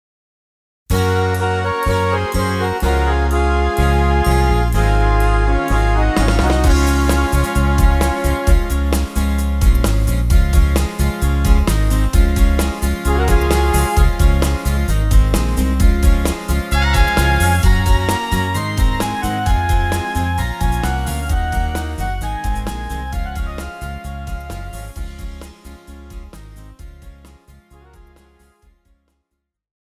Žánr: Pohádkové
BPM: 125
Key: F#
MP3 ukázka